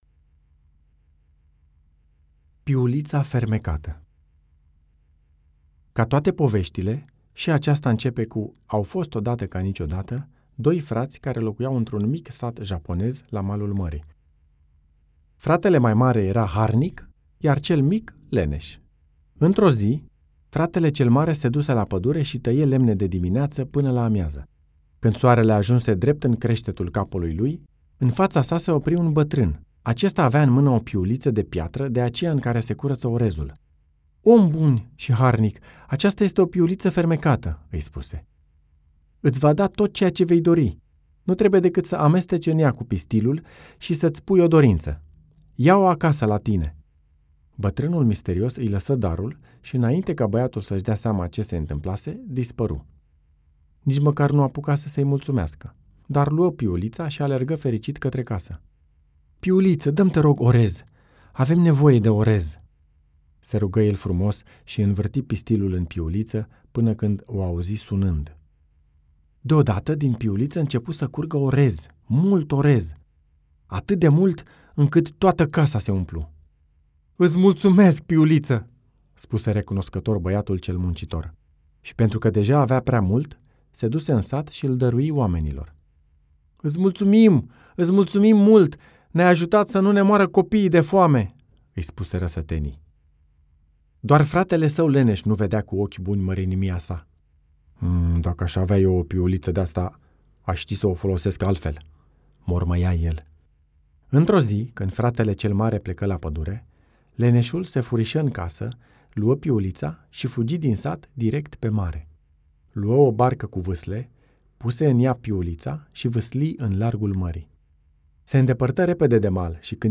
Aşağıdaki player butonu üzerinden hikayeyi dinleyebilirsiniz.